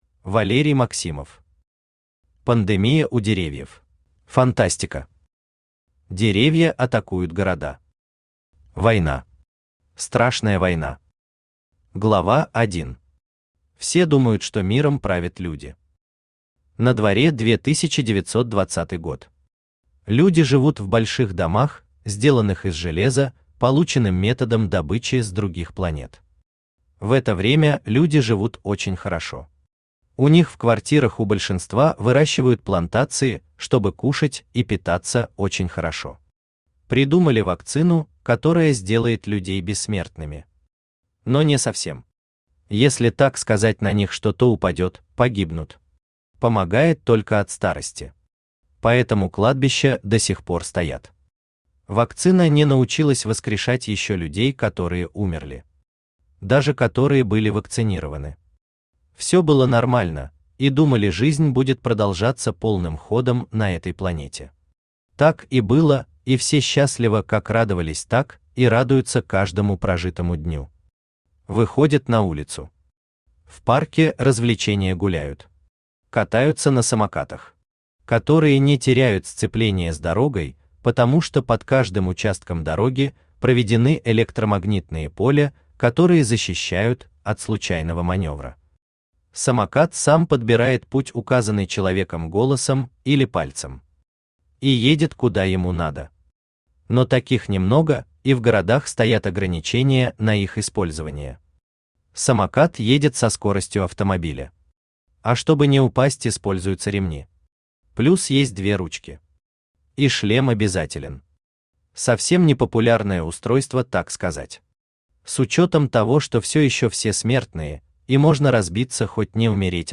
Аудиокнига Пандемия у Деревьев | Библиотека аудиокниг
Aудиокнига Пандемия у Деревьев Автор Валерий Сергеевич Максимов Читает аудиокнигу Авточтец ЛитРес.